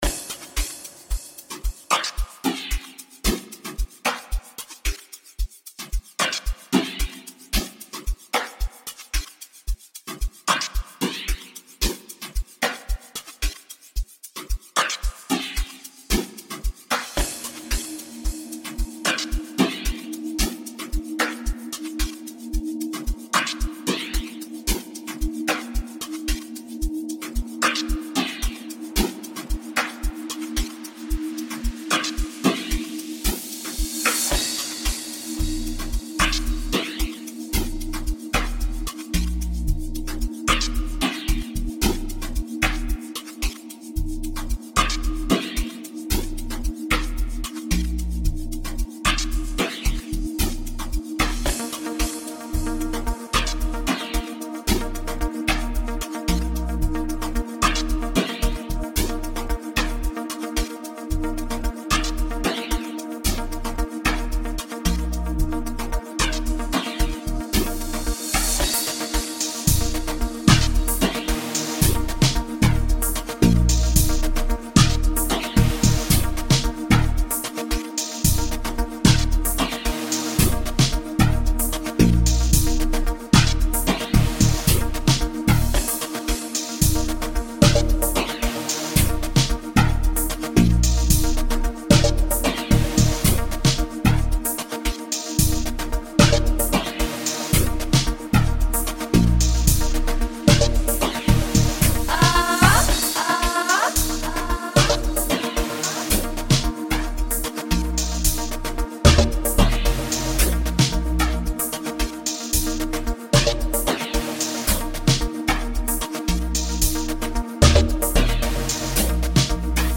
soulful, energetic vibes